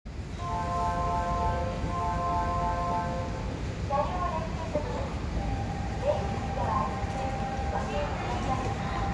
60000形 ミュージックホーン
※　音量注意
地下鉄駅入線および発車の際には鳴らすことが多いようです。
音量は大きく、VSEと全く同じ音です。